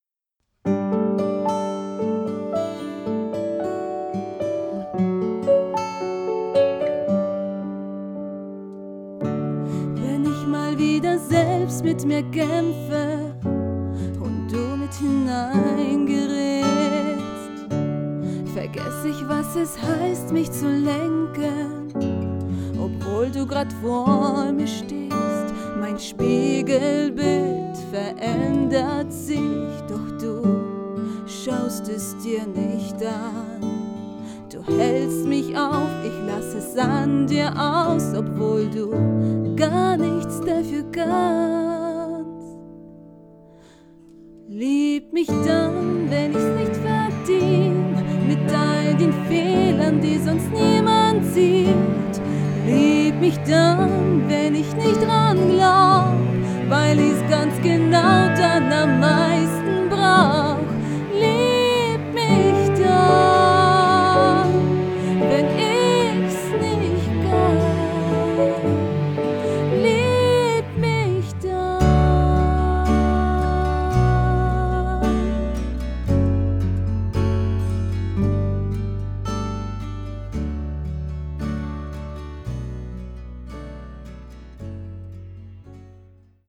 Hochzeitssängerin
Berührender Live-Gesang